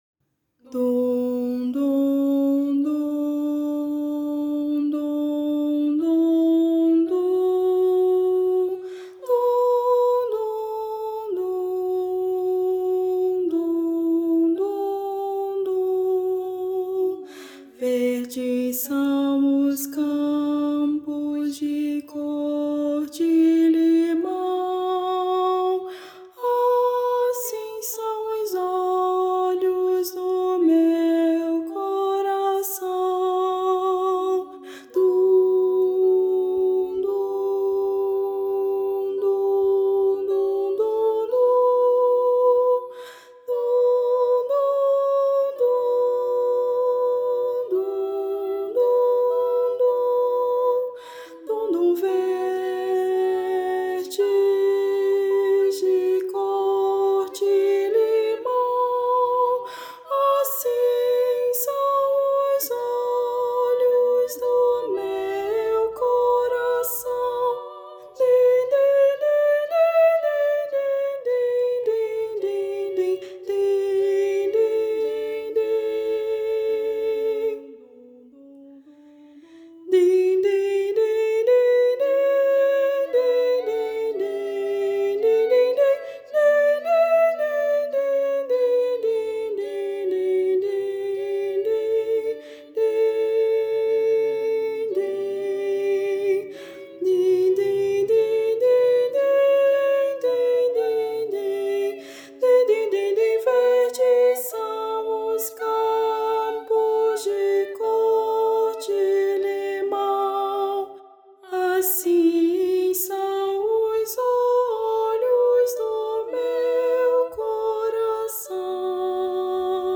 para coro infantil a três vozes
Voz Guia 2